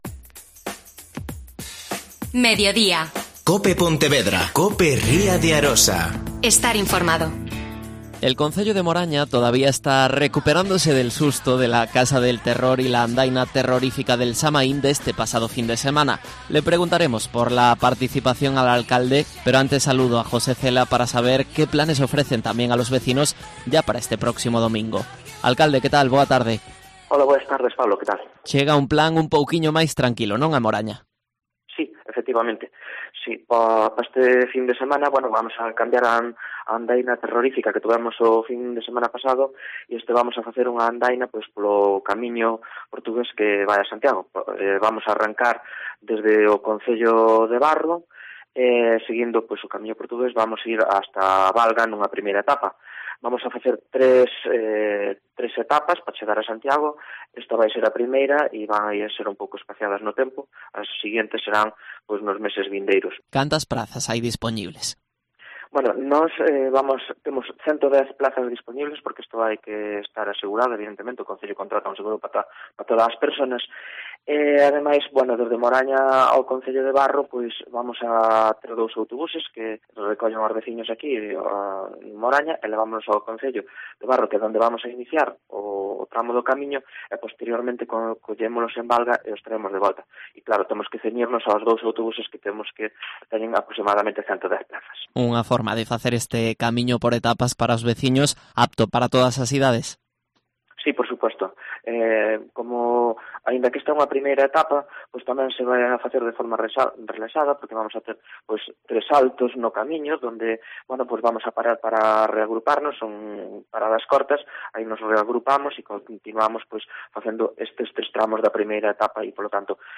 Entrevista a José Cela, alcalde de Moraña